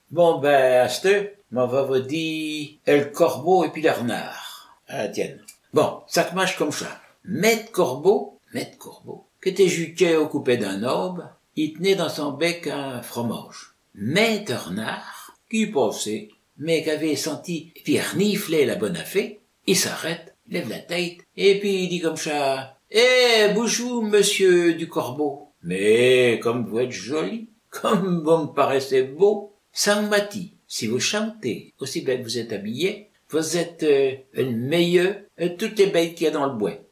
20 April 2023 at 9:25 pm I found it quite fascinating to hear the words “tête” pronounced as “téte” as well as “bois” pronounced as “boé”.